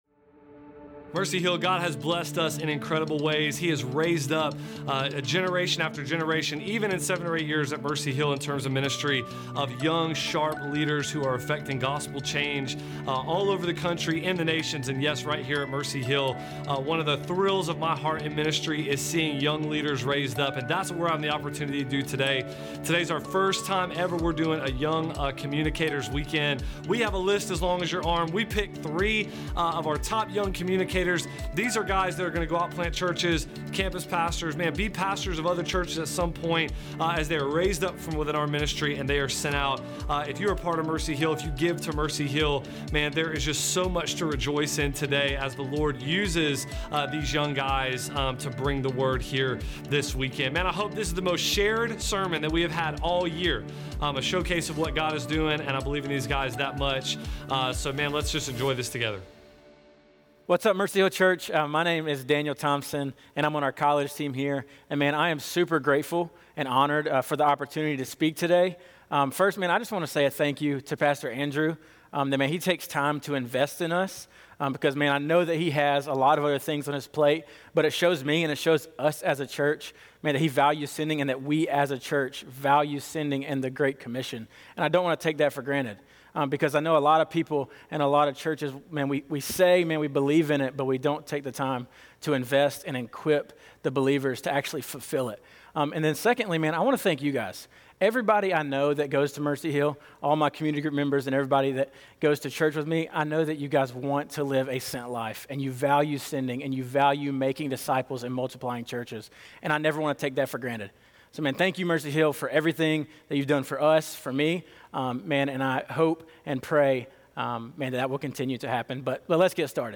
This service features 3 short messages from